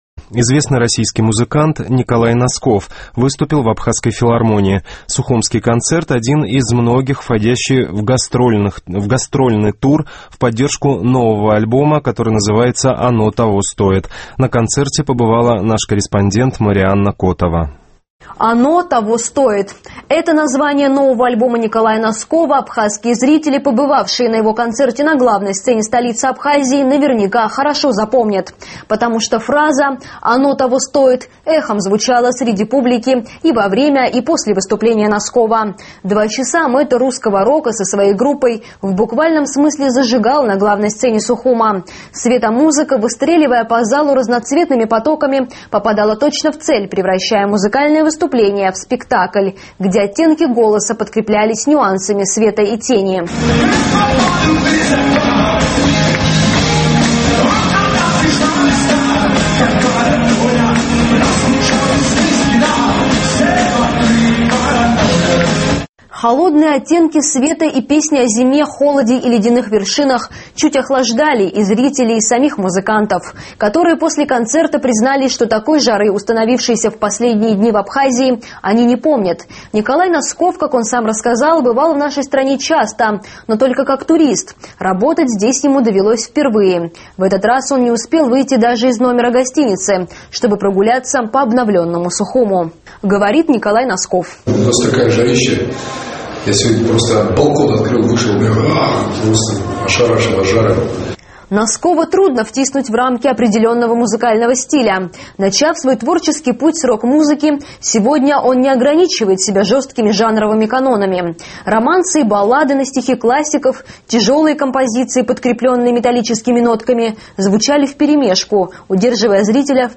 СУХУМИ--Николай Носков выступил в Абхазгосфилармонии. Сухумский концерт – один из многих, входящих в гастрольный тур в поддержку нового альбома «Оно того стоит».
Романсы и баллады на стихи классиков, тяжелые композиции, подкрепленные металлическими нотками, звучали вперемешку, удерживая зрителя в приятном напряжении.